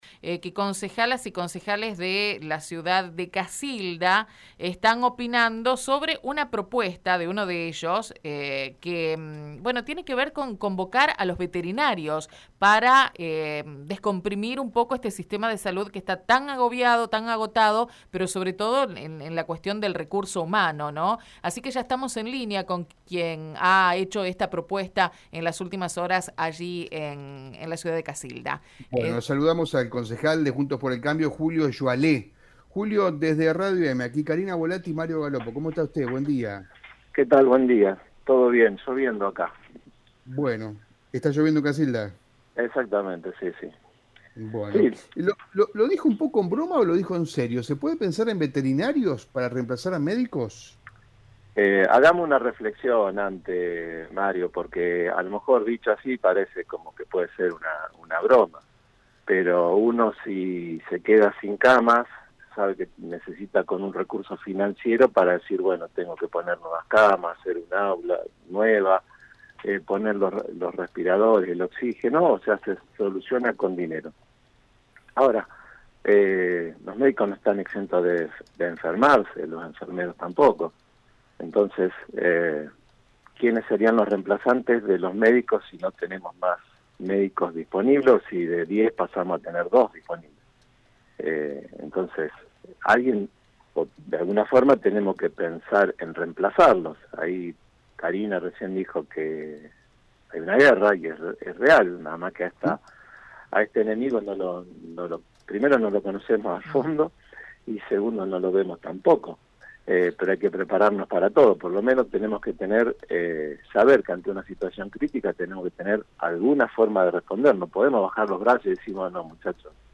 En diálogo con Radio EME el edil brindó detalles de la propuesta.